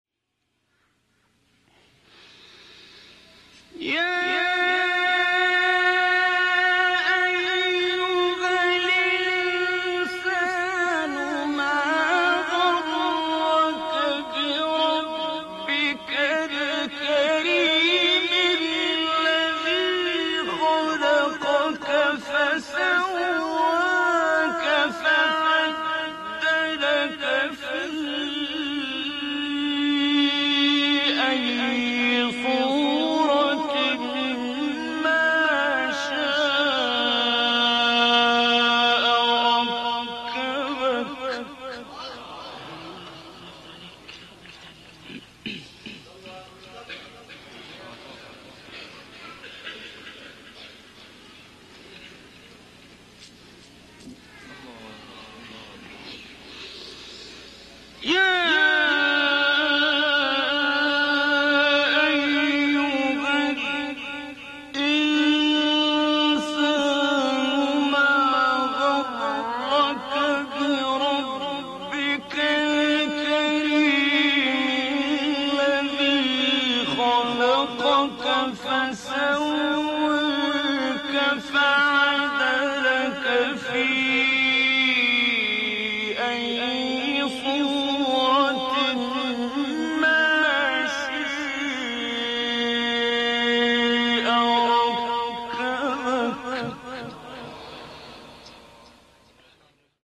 قطعات شنیدنی از تلاوت سوره مبارکه انفطار را با صوت قاریان محمد اللیثی، شحات محمد انور، عبدالباسط محمد عبدالصمد، محمد صدیق منشاوی و راغب مصطفی غلوش می‌شنوید.
آیات ۶ تا ۸ سوره انفطار با صوت عبدالباسط محمد عبدالصمد